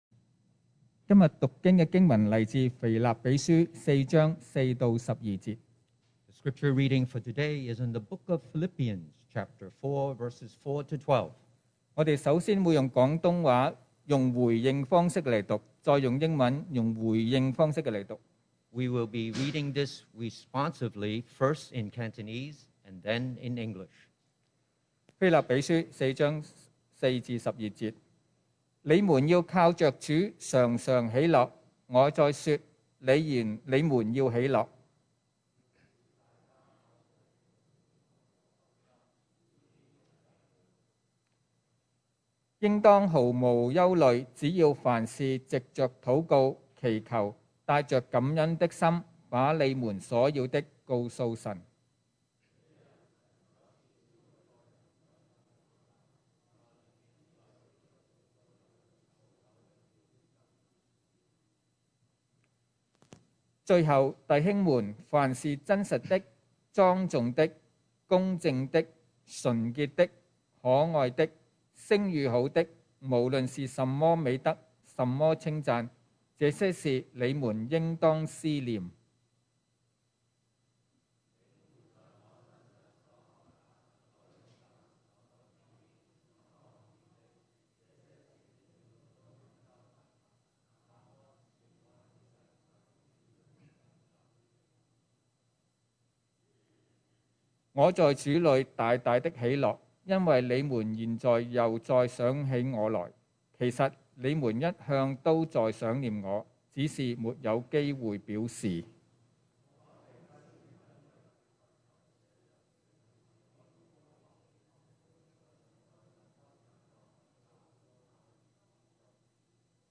2022 sermon audios
Service Type: Sunday Morning